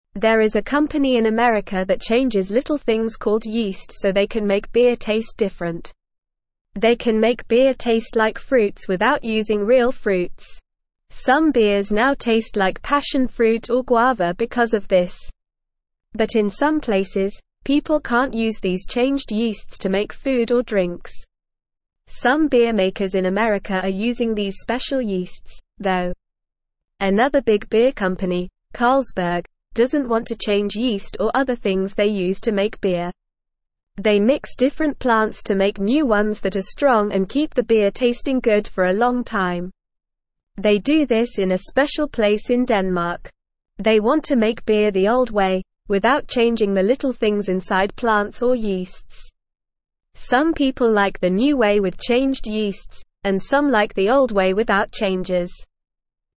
Normal
English-Newsroom-Beginner-NORMAL-Reading-Changing-Yeast-to-Make-Beer-Taste-Different.mp3